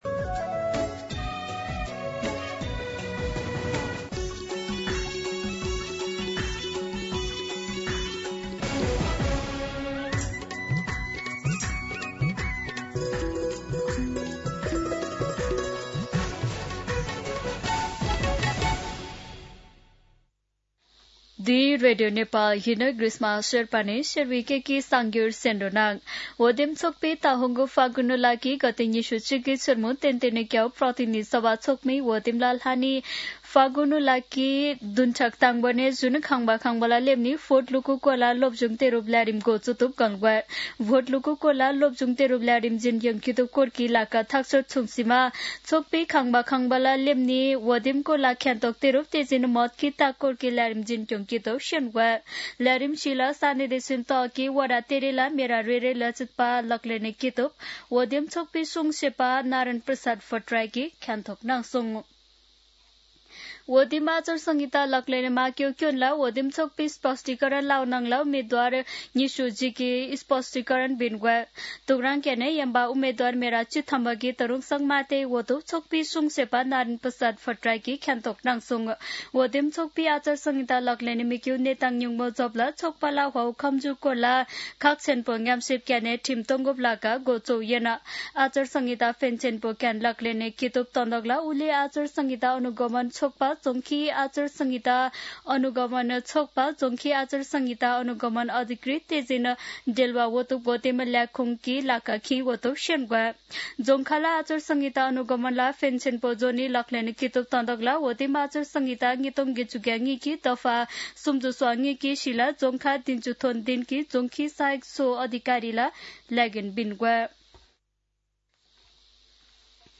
शेर्पा भाषाको समाचार : २४ माघ , २०८२
Sherpa-News-10-24.mp3